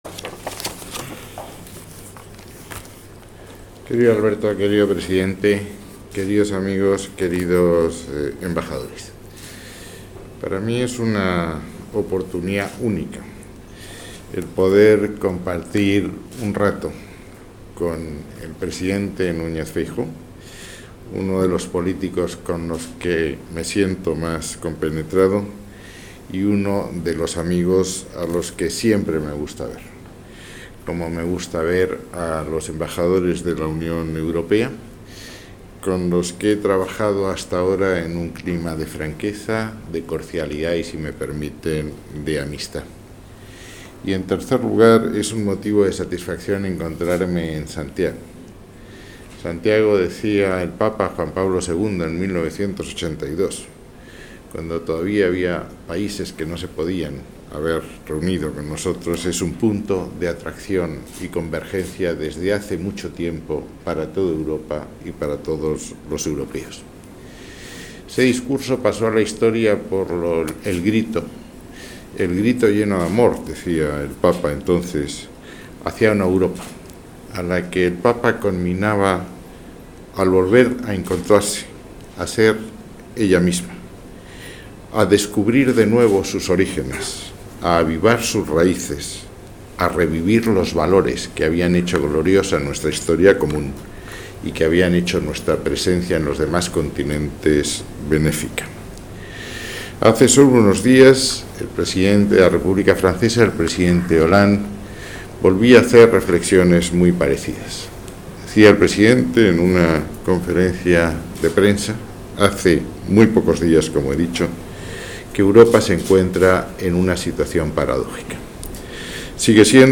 Il s'ouvre dans une nouvelle fenêtre: Intervención del Ministro.mp3